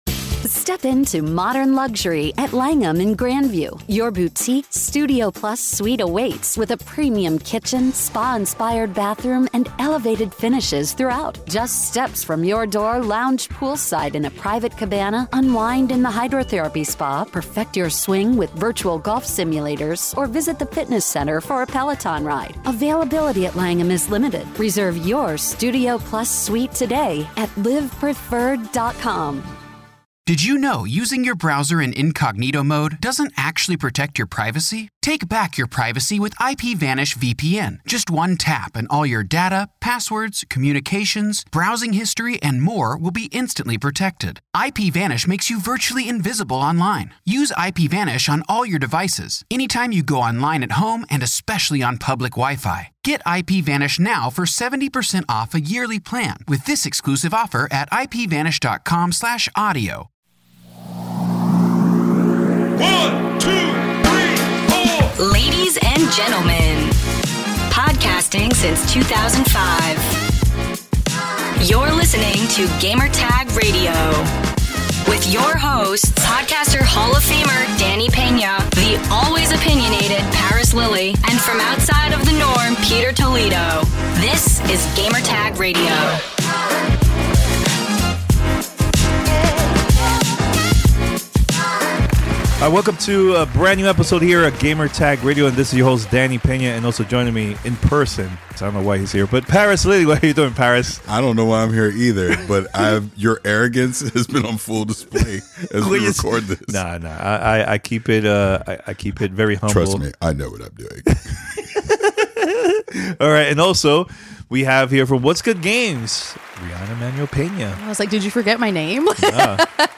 Redfall Hands-On Impressions and Interview